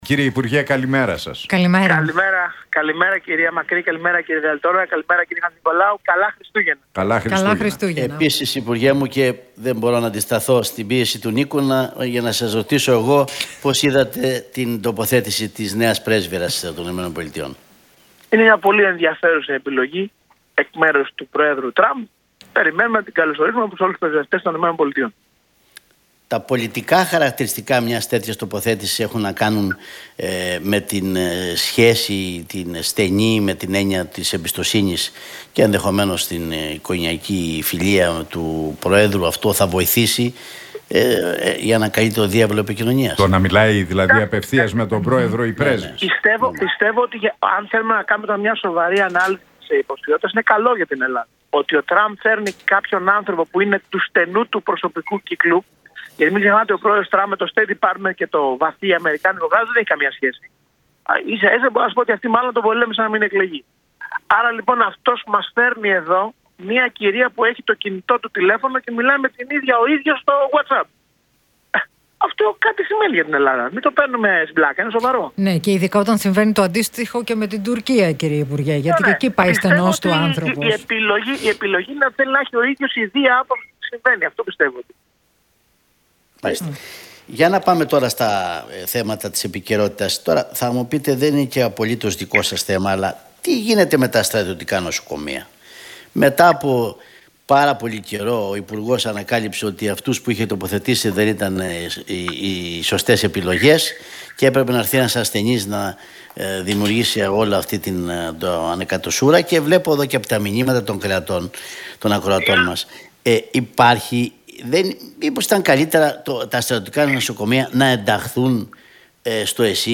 Γεωργιάδης στον Realfm 97,8: Ο έκτος χρόνος μιας διακυβέρνησης είναι ιστορικά ο χειρότερος - Τι είπε για τα στρατιωτικά νοσοκομεία